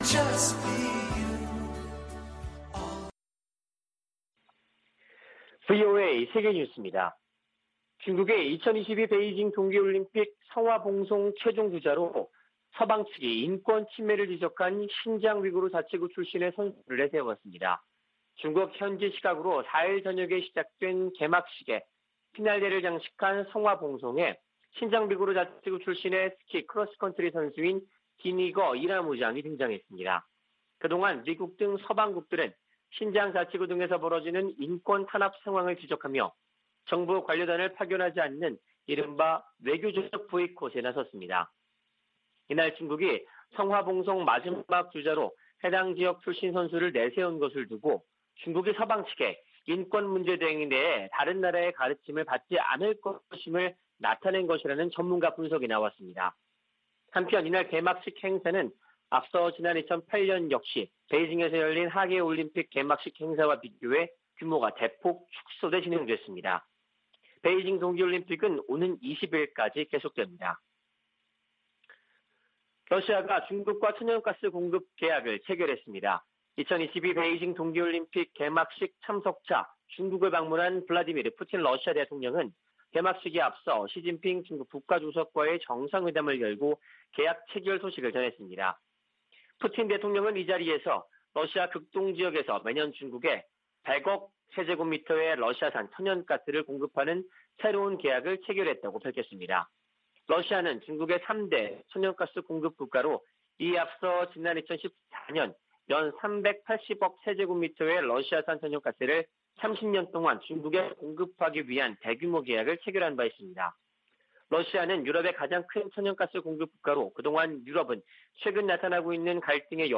VOA 한국어 아침 뉴스 프로그램 '워싱턴 뉴스 광장' 2021년 2월 5일 방송입니다. 미 국무부는 북한의 탄도미사일 발사에 대응해 동맹과 파트너는 물론, 유엔과 협력하고 있다고 밝혔습니다. 미군 당국이 일본·호주와 실시 중인 연합 공중훈련의 목적을 설명하면서 북한을 거론했습니다. 호주가 북한의 최근 잇단 미사일 발사를 규탄하며 대량살상무기와 탄도미사일 추구는 국제 평화와 안보에 중대한 위협이라고 지적했습니다.